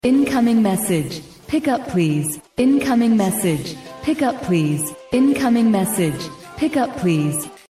New Message Ringtone 🔔 | sound effects free download